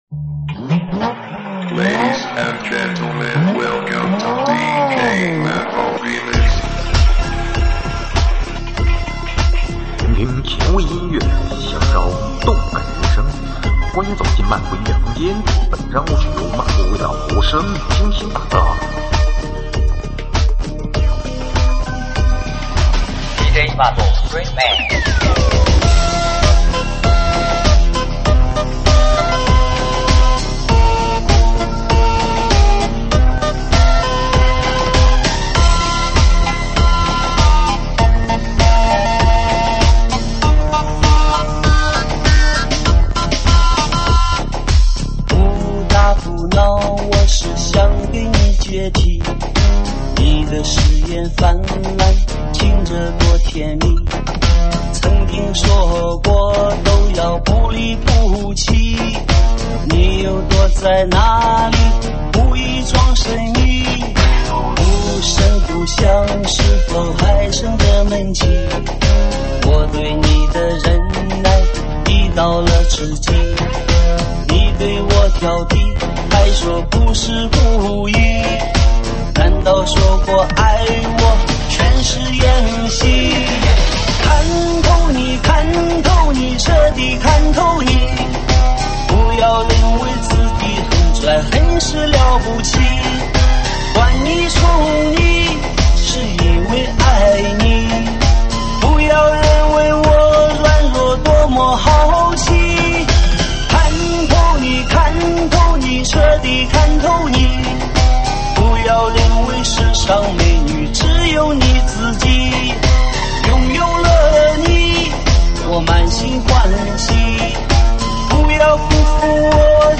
舞曲类别：喊麦现场